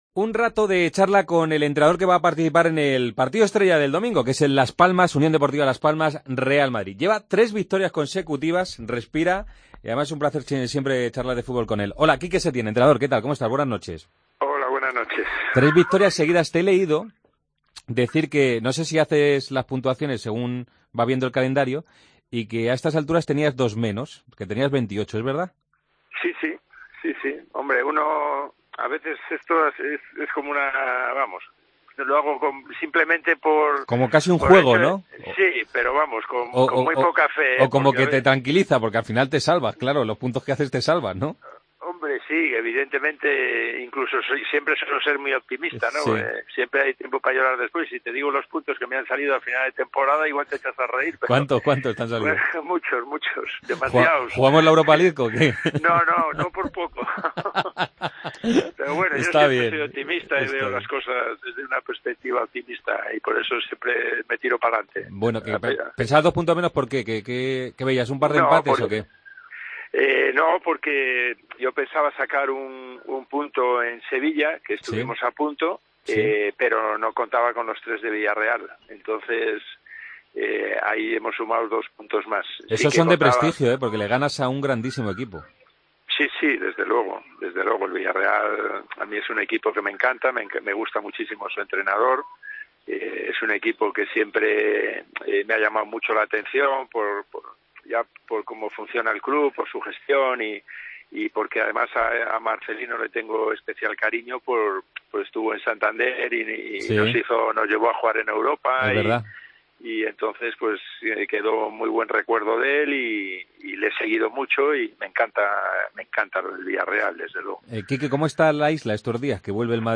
Hablamos con el entrenador de Las Palmas antes del partido de este domingo ante el Real Madrid. Setién habla de la reacción del equipo en los últimos partidos: "La isla está ilusionada con el equipo.